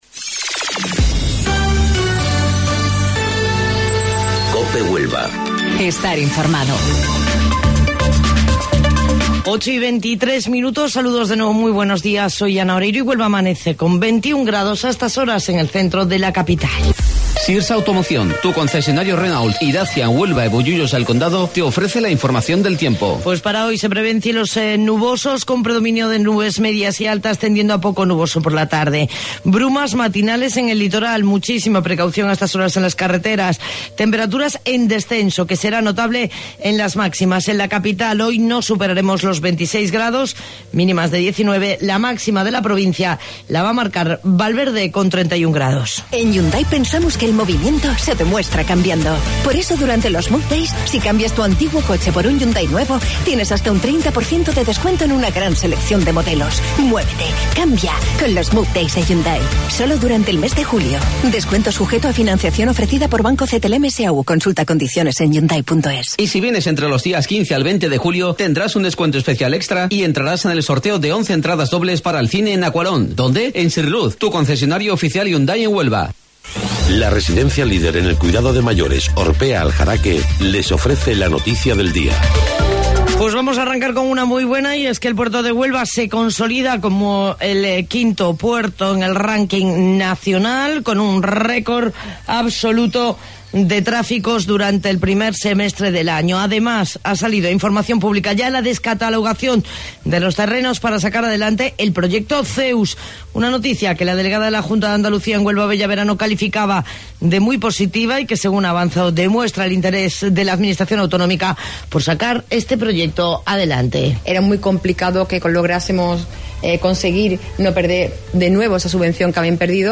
AUDIO: Informativo Local 08:25 del 12 de Julio